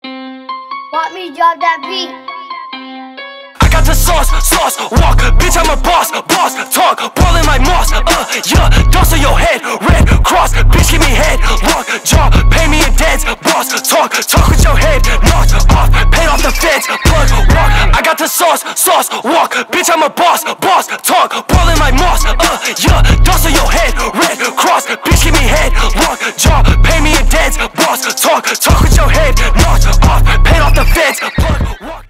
Рэп и Хип Хоп
громкие